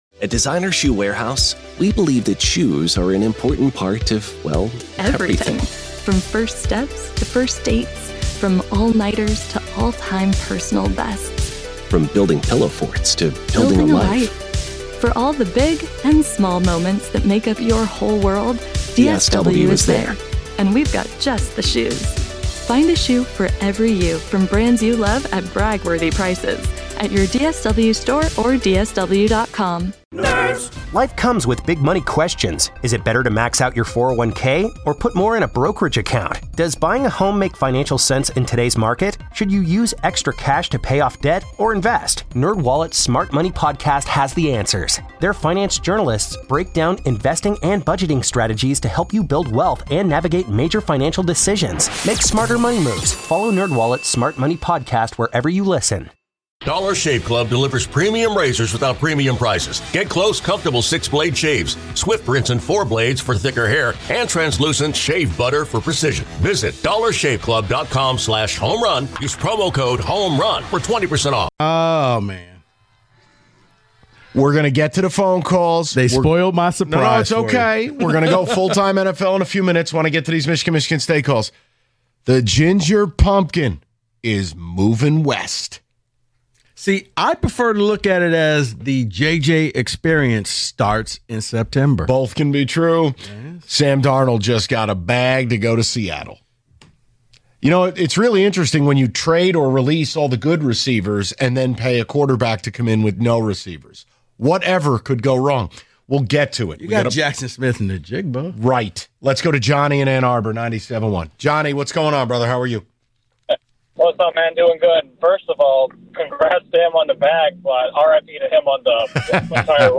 Taking Your Calls On Michigan-MSU Fiasco